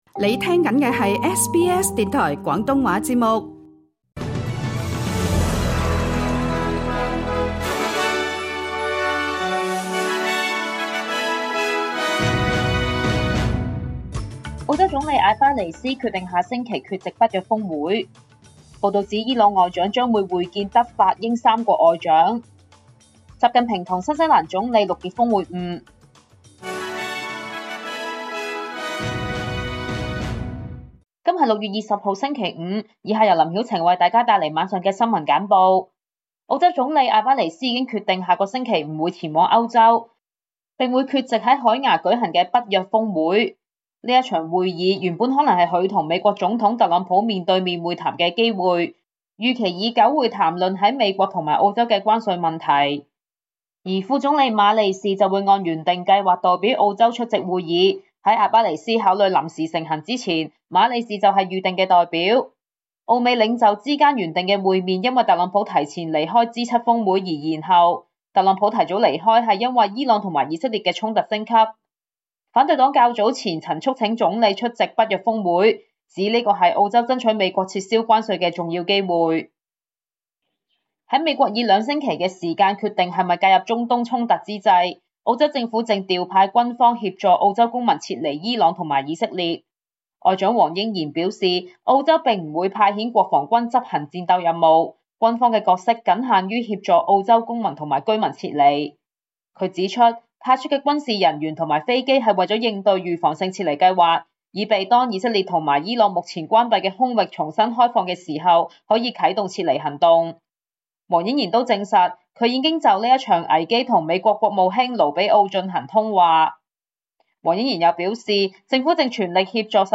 SBS 廣東話晚間新聞